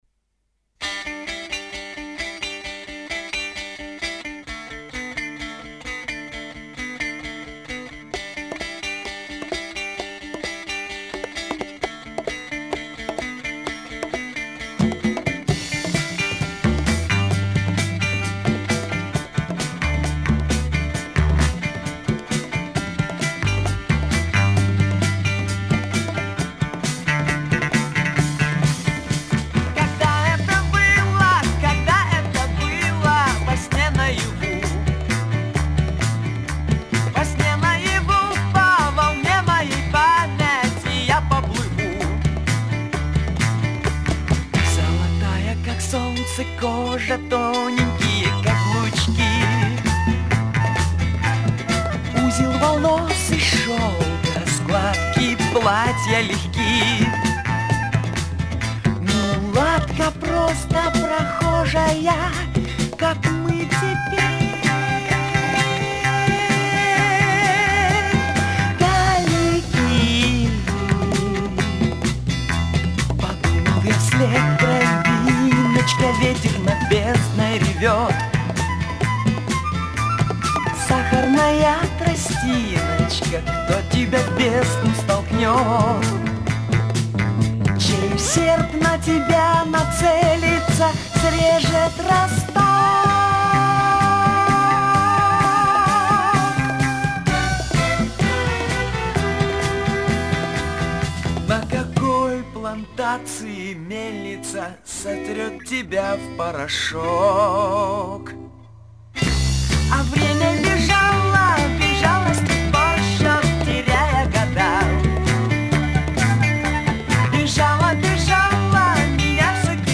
он выпускает концептуальный альбом в стиле арт-рок.